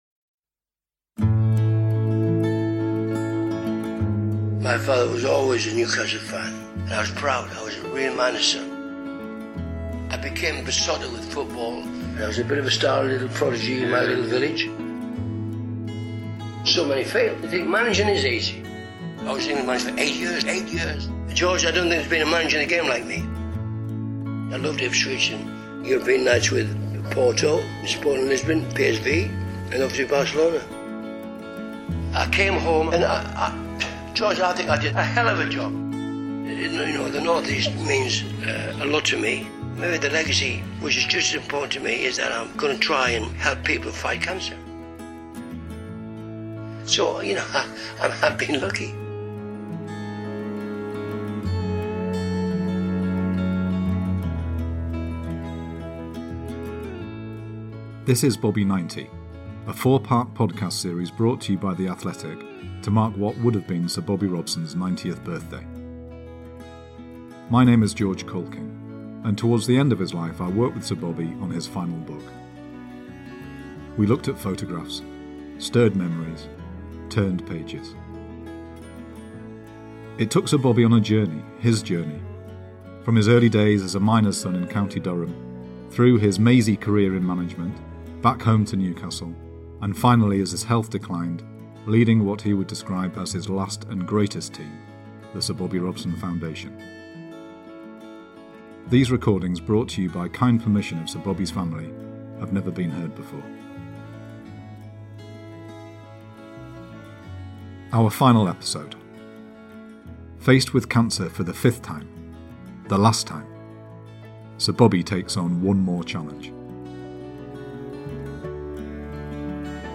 Thanks to the generosity of Sir Bobby's family we are able to publish the recording of those conversations, featuring memories of his life as a miner's son, watching some of the greatest ever Newcastle United teams, and embarking on an incredible football career of his own.